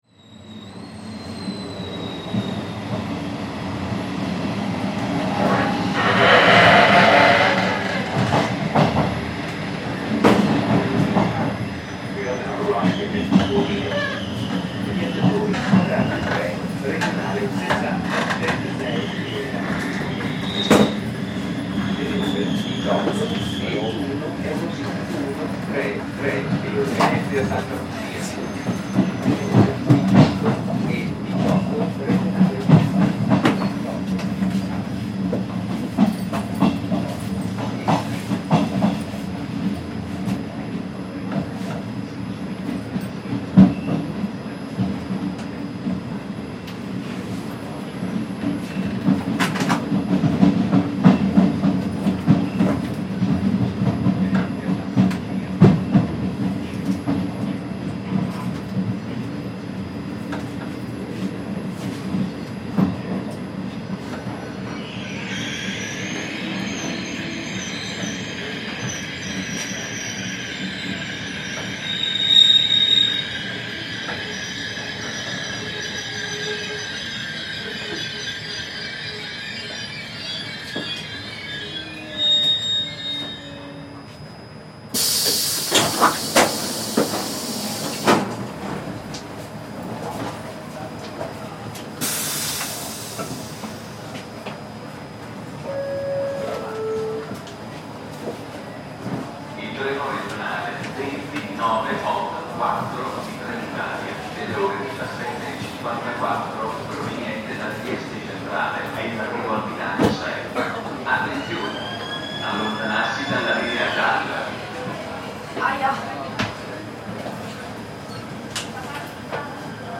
In the recording, we hear the arrival of Trenitalia 20984, a regional train headed to Trieste Centrale station. As the train comes to a stop, voices of Italians and migrants are audible, suggesting a diverse mix of individuals disembarking.
Part of the Migration Sounds project, the world’s first collection of the sounds of human migration.